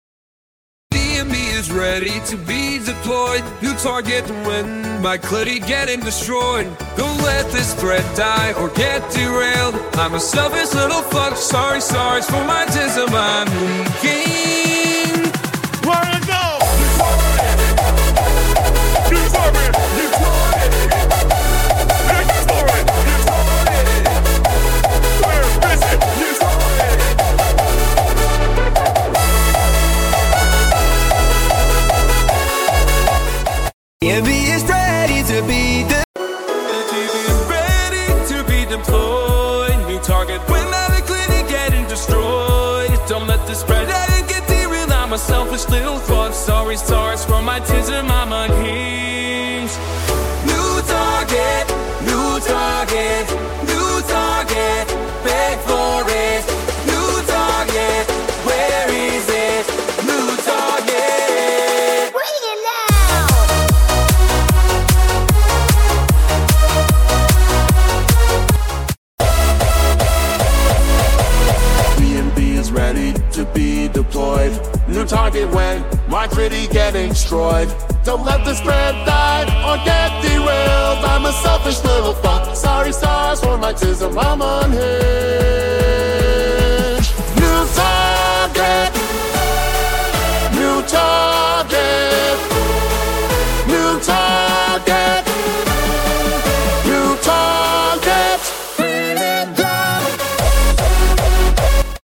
NU TARGET RAVE.mp3 📥 (1.61 MB)